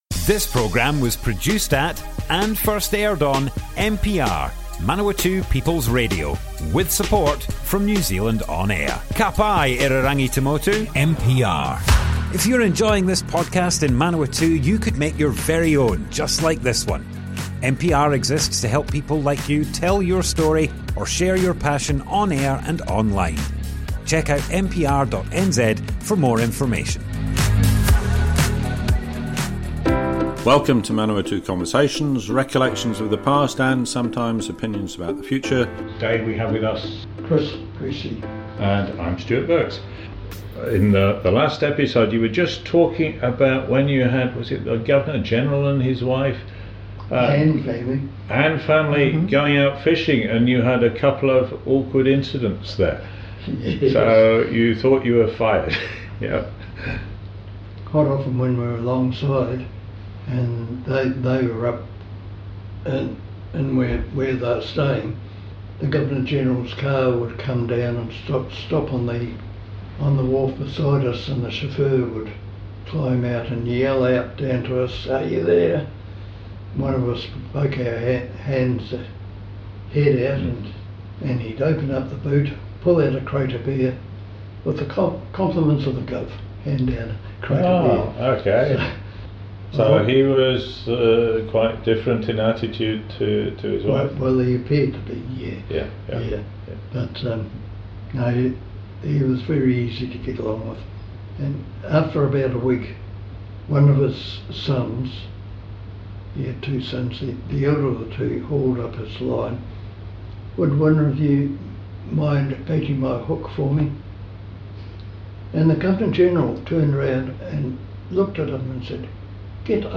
Manawatu Conversations More Info → Description Broadcast on Manawatu People's Radio, 24th June 2025.
oral history